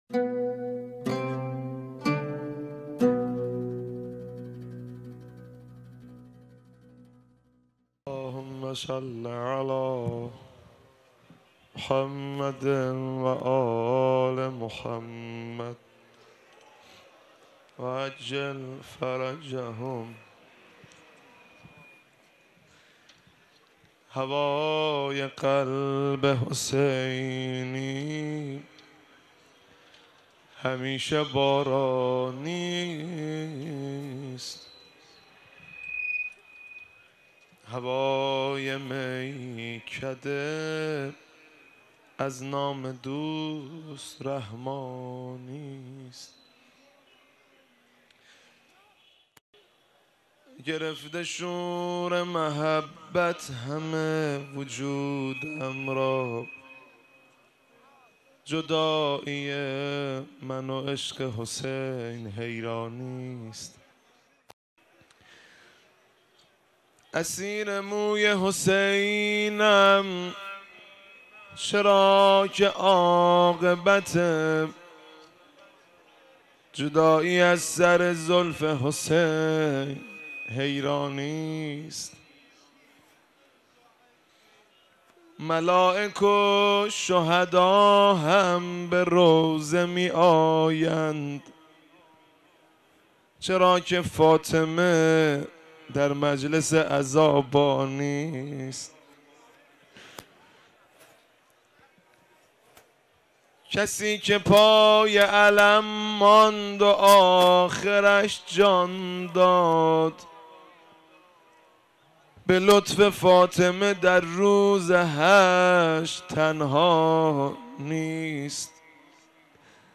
روضه | هوای قلب حسینی، همیشه باران‌یست
ویژه برنامه بین المللی آغاز راه‌پیمایی اربعین و بزرگداشت شهدای جهان السلام
نجف اشرف، پل ثورةالعشرین